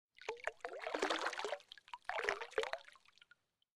Шум движения рыбы в воде